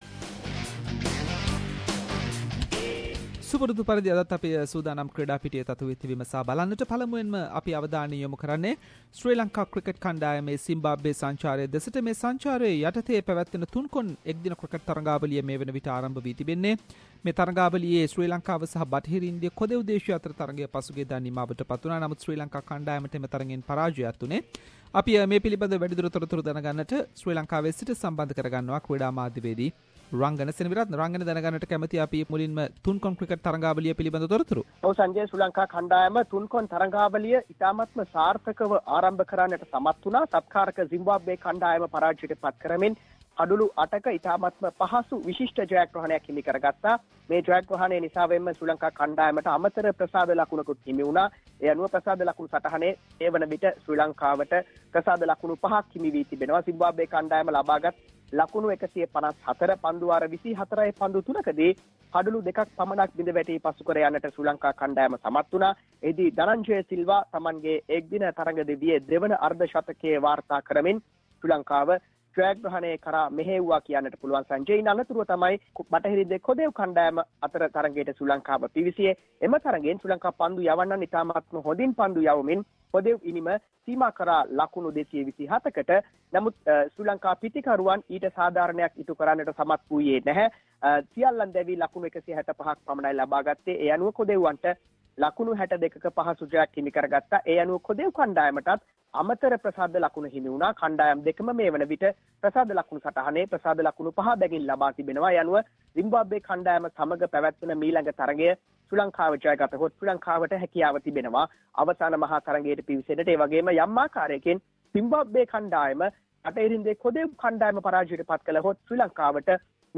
In this weeks SBS Sinhalese sports wrap…. Latest from Sri Lanka cricket tour to Zimbabwe, Latest from South Africa cricket tour to Australia and many more local and international sports news.